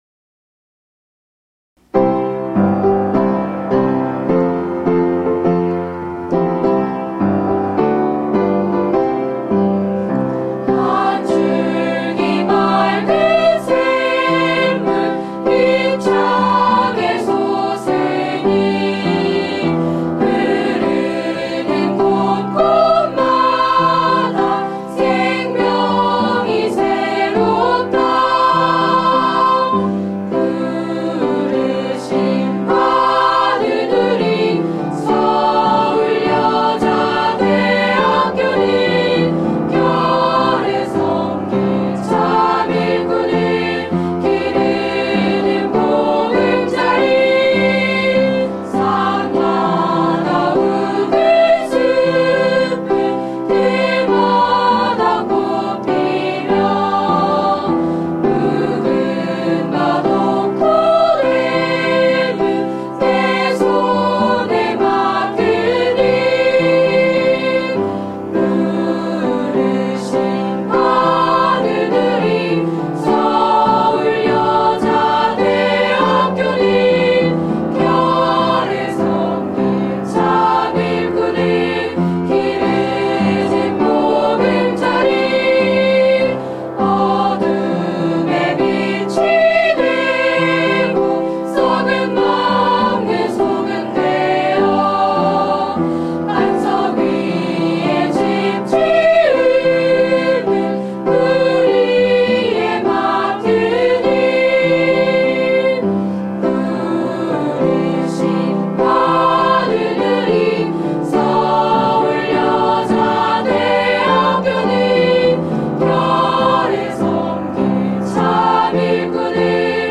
교가 교가는 1961년 고황경 초대학장이 작사 및 작곡한 것으로 새 한국을 건설할 일꾼으로서 사회혁신을 이바지 할 수 있는 새 힘을 기르고 새 정신을 준비하도록 격려하는 내용으로 되어 있다. 1963년 관현악곡으로 이동훈 교사(중앙여고)가 편곡하여 지금까지 사용하였으며, 2012년에 보다 힘차고 웅장한 곡으로 이선택 작곡가(성남시립합창단)가 재편곡하였다.